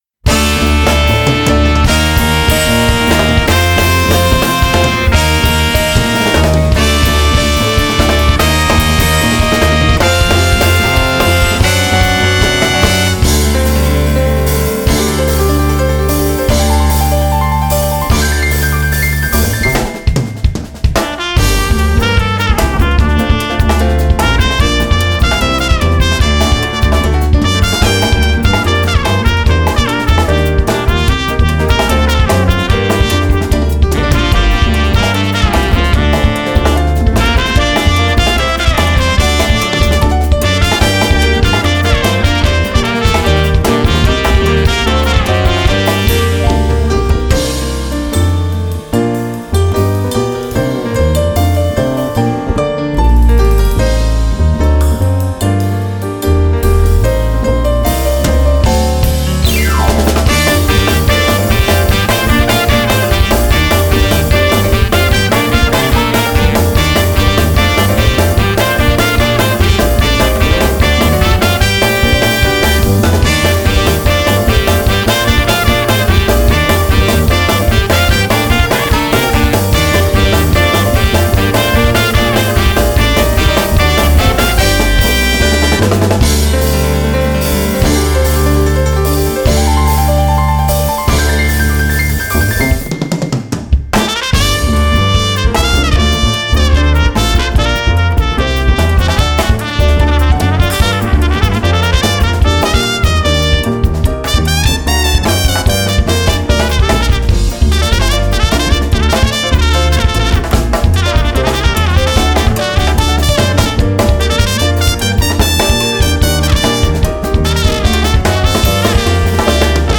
Genre: Indie.